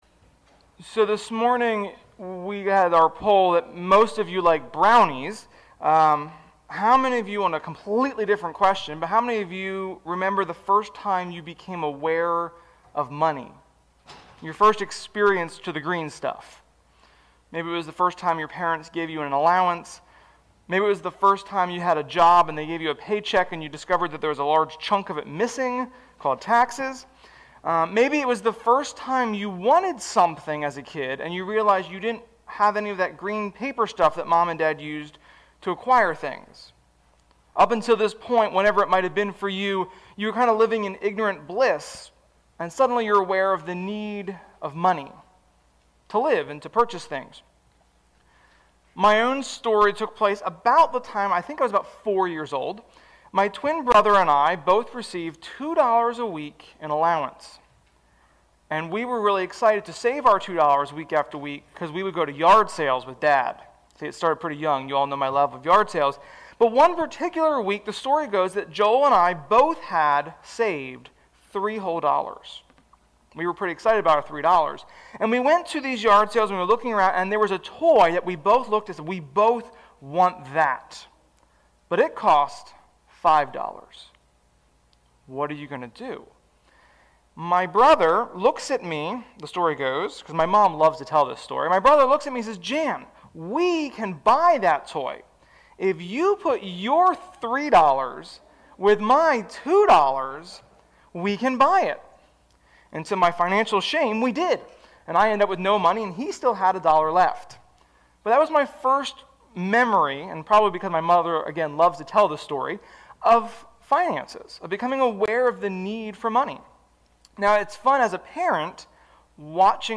Sermon-3.7.21.mp3